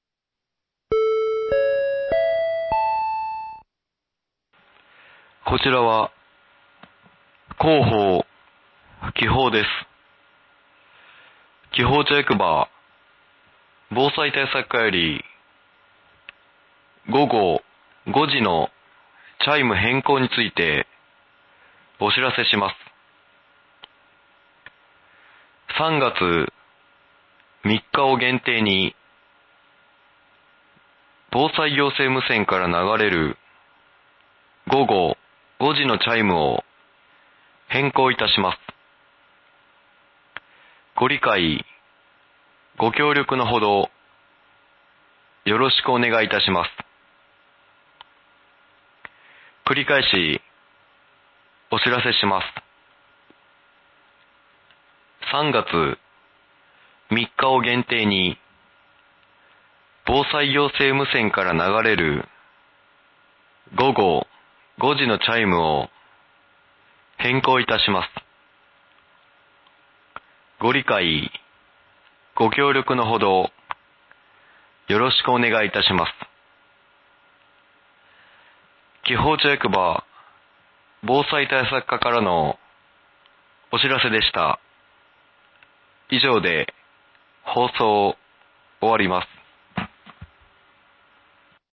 紀宝町役場 防災対策課より、防災行政無線から放送される、チャイム音の変更についてお知らせします。 ３月３日を限定に、防災行政無線から流れる午後５時のチャイムを変更いたします。